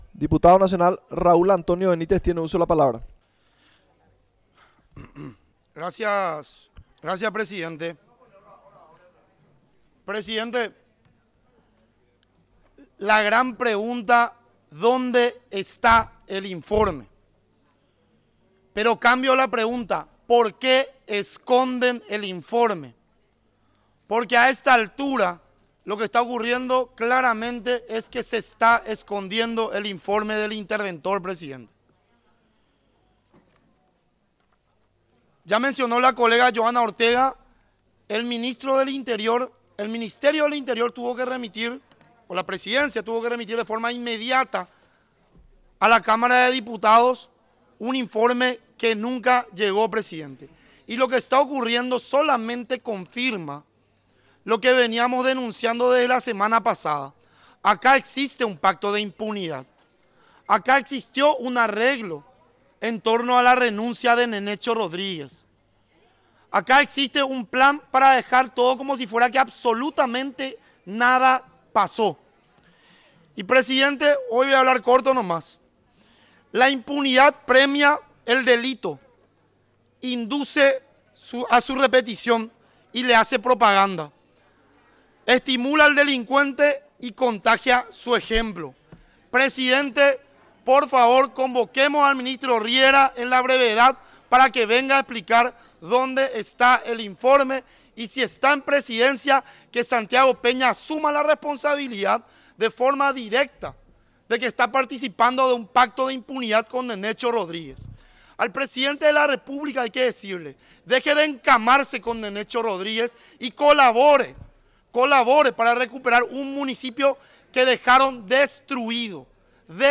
Sesión Extraordinaria, 26 de agosto de 2025
Exposiciones verbales y escritas
01 - Dip Johana Ortega